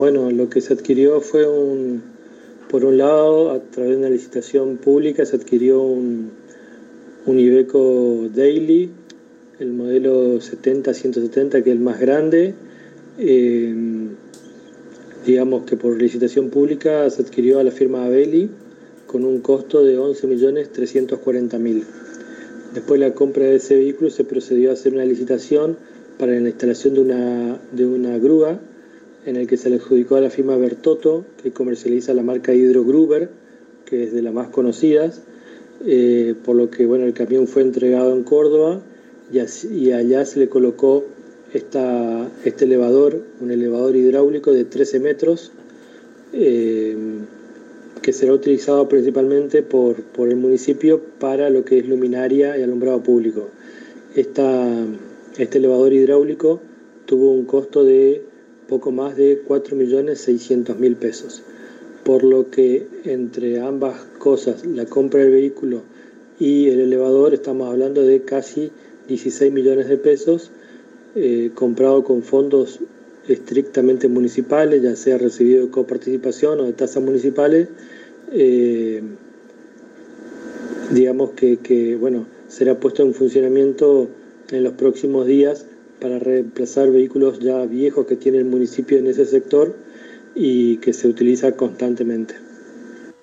En diálogo exclusivo con la ANG el Secretario de Hacienda de Apóstoles Javier Safrán comentó sobre la nueva adquisición que realizó la gestión municipal para reemplazar vehículo y grúa hidráulica en el sector de alumbrado público.
Audio: Javier Safrán Sec. Hac. Apóstoles